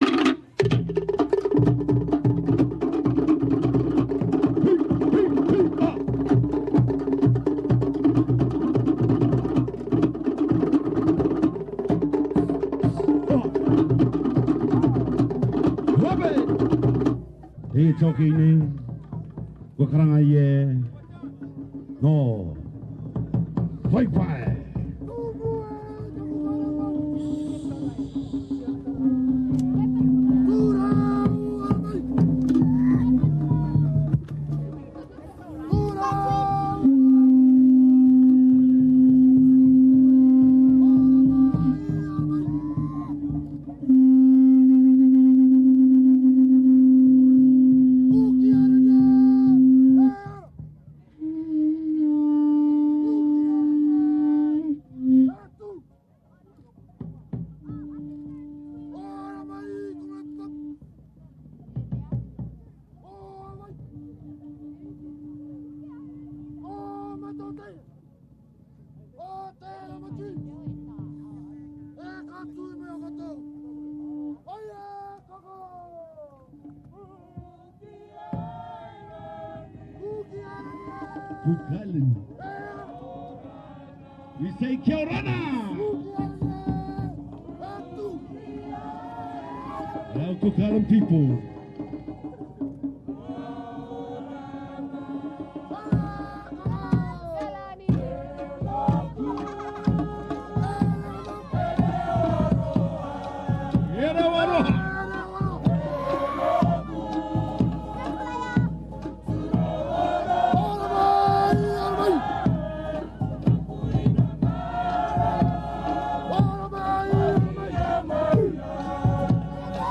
The Rama Team was one of those that performed at Sydney's Te Maeva Nui 2017. They were just as loud and appeared to be enjoying their time joining others to celebrate a great day in our island nation's history.